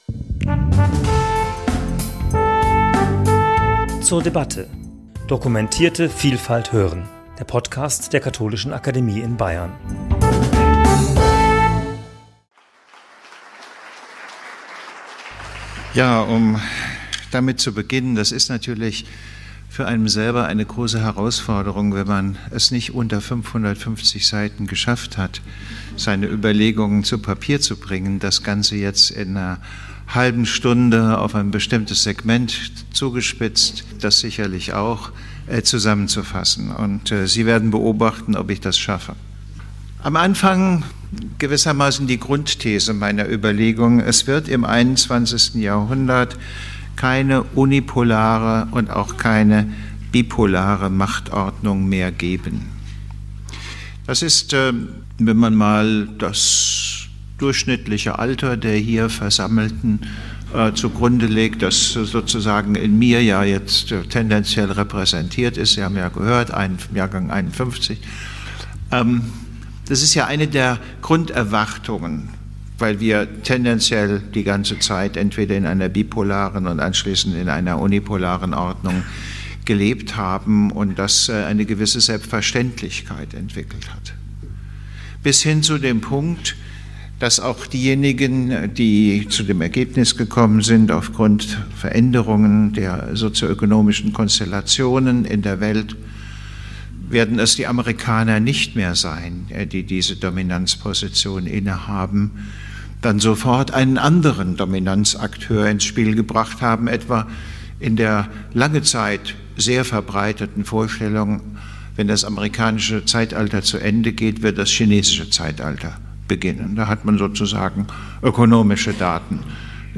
In seinem Referat am 10.4.2024 in der Katholischen Akademie in Bayern analysiert der bekannte Politikwissenschaftler, welche Risiken die sich dramatisch veränderte Weltlage - besonders, aber nicht nur für die EU - mit sich bringt.